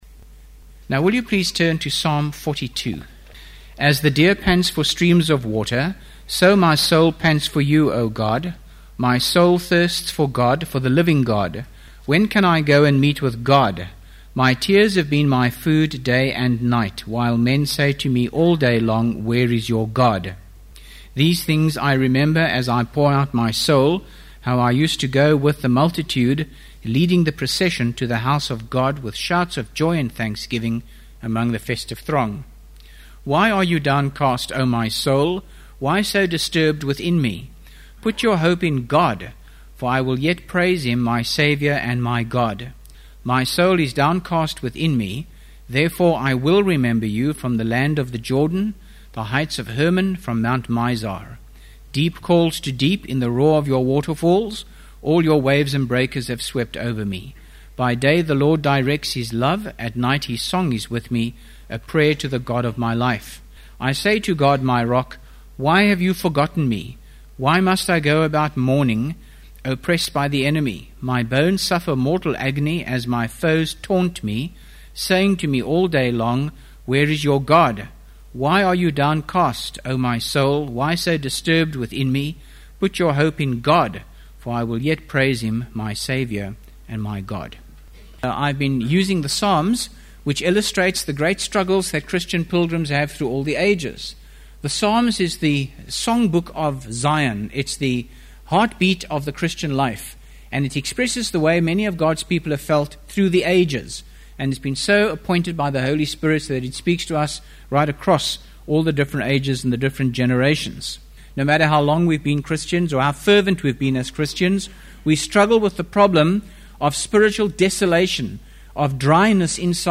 by Frank Retief | Jan 21, 2025 | Frank's Sermons (St James) | 0 comments